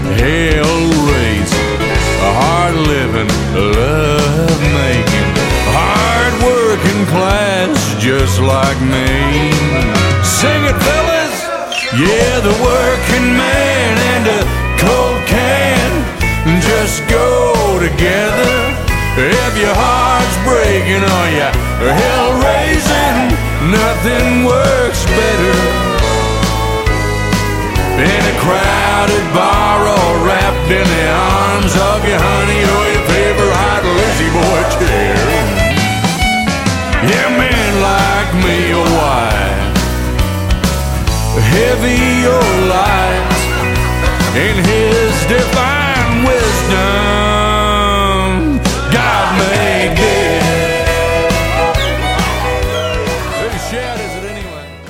Country & Western Hits